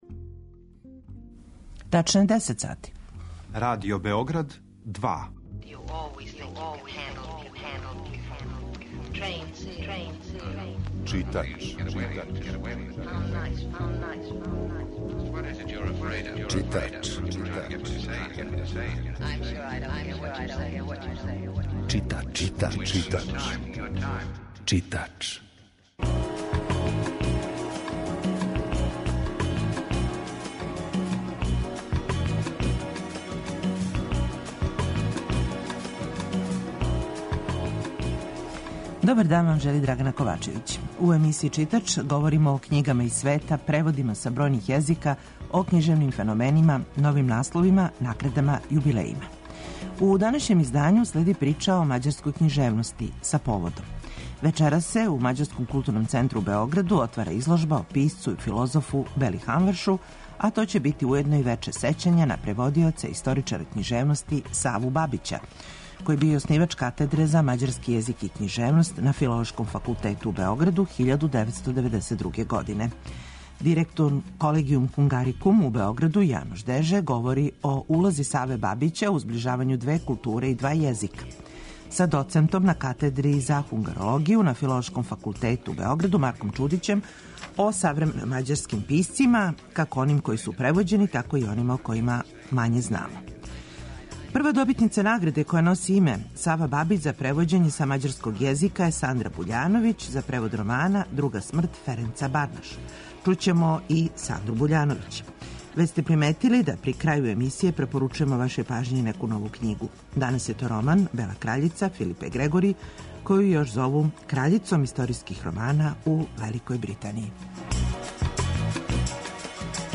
Разговарамо о мађарској књижевности